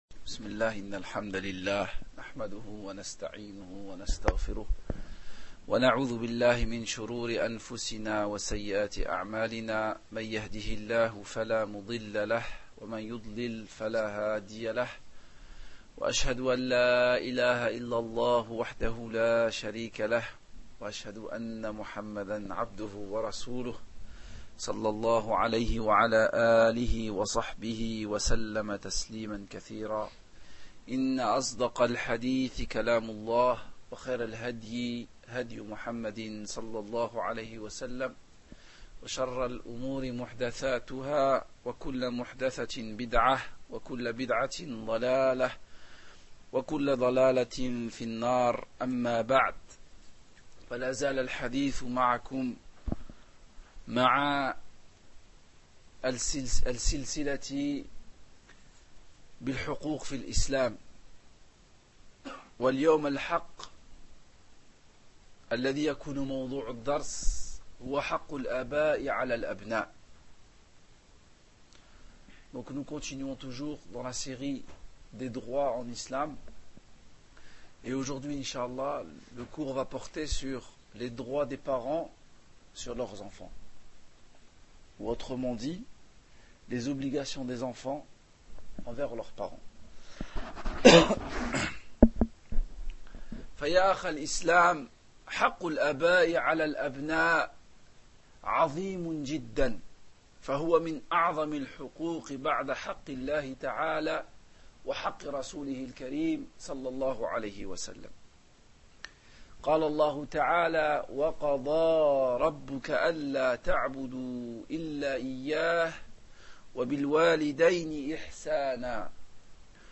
faite dans la salle de prière de la résidence Camus de Villeneuve d’Ascq